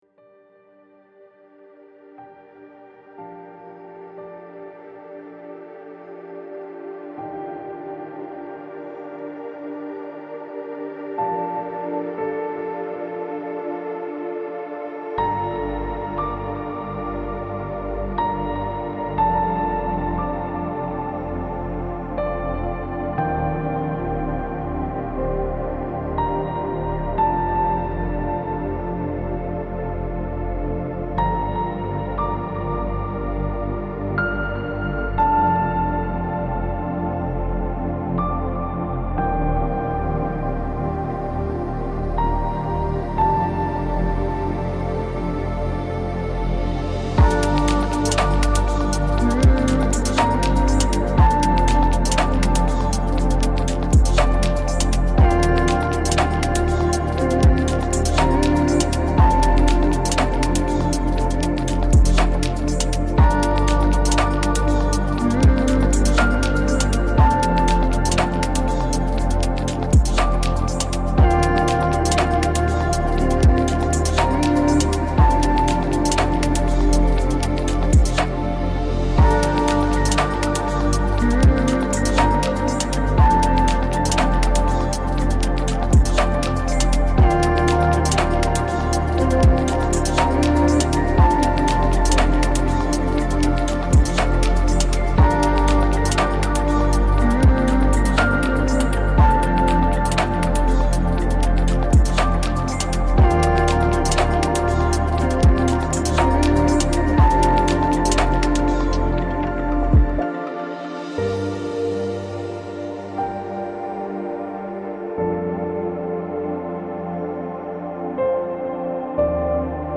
Мелодия для медитации